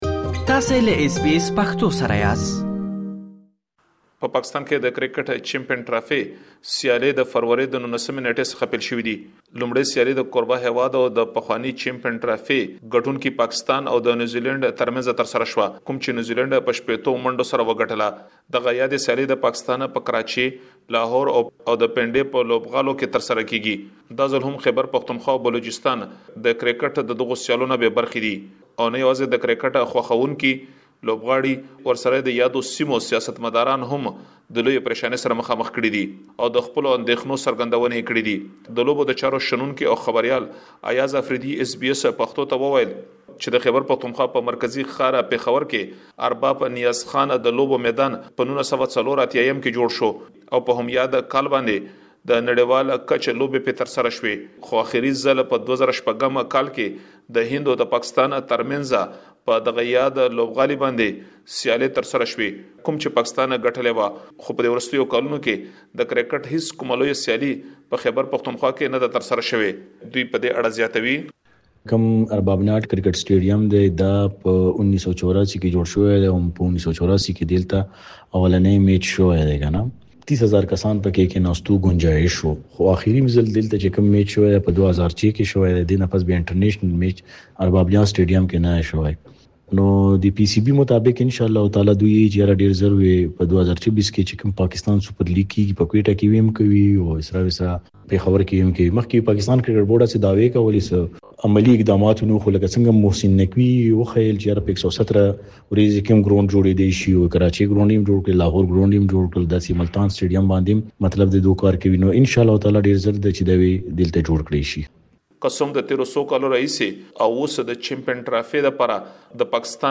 رپوټ